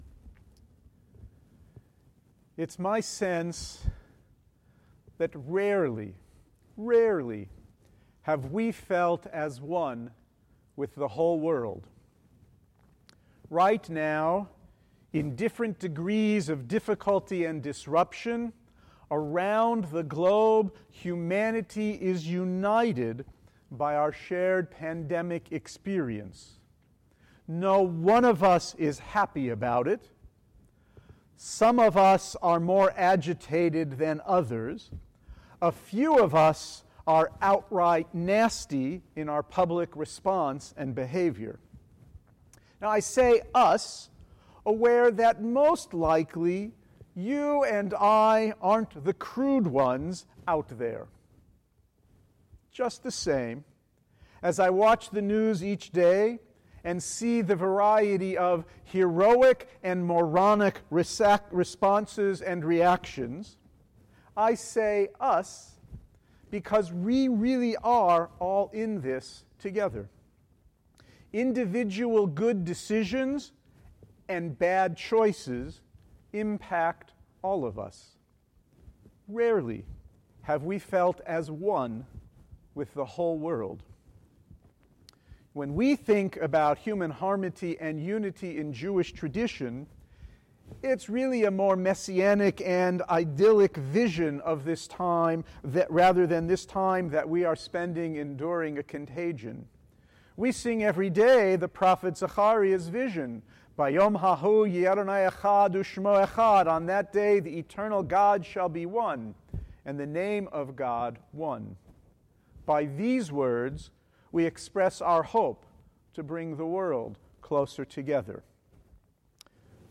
Sermon or written equivalent